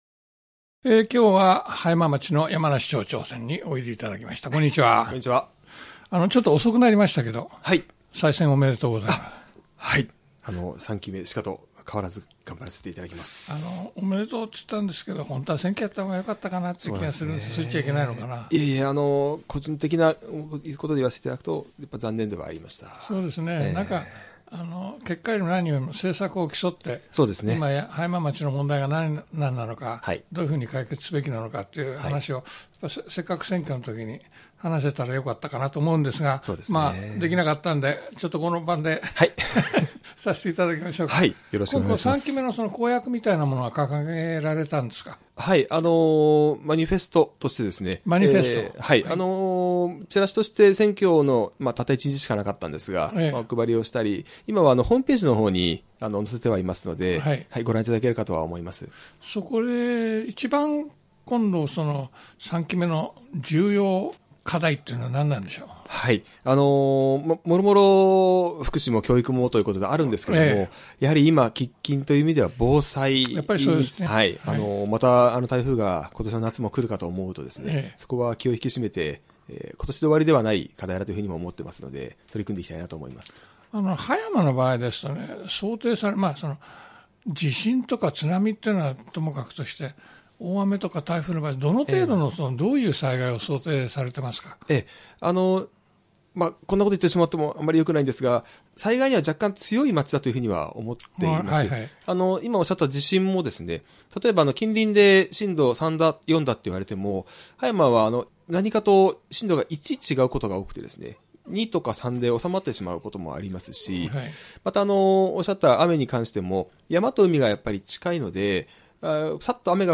【アーカイブ】2020年1月25日放送 葉山町長インタビュー
葉山 山梨町長に木村太郎（湘南ビーチＦＭ代表取締役）がインタビューを行いました。